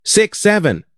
This trending sound effect is perfect for meme creation, gaming & entertainment.